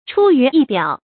出于意表 注音： ㄔㄨ ㄧㄩˊ ㄧˋ ㄅㄧㄠˇ 讀音讀法： 意思解釋： 謂出乎人的意料之外。